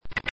tick2.mp3